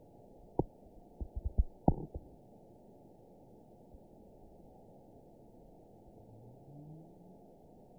event 920300 date 03/14/24 time 18:58:50 GMT (1 month, 2 weeks ago) score 8.15 location TSS-AB04 detected by nrw target species NRW annotations +NRW Spectrogram: Frequency (kHz) vs. Time (s) audio not available .wav